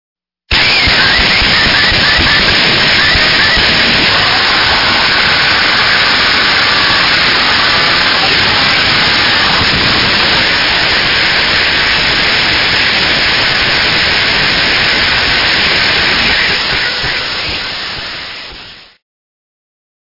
絶叫とノイズのコラージュが織りなす美しき雑音